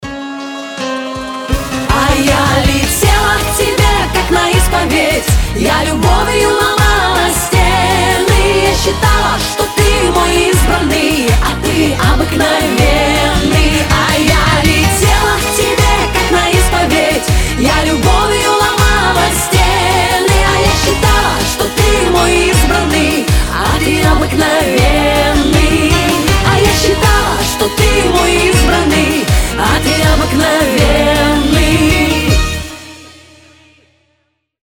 веселые
эстрадные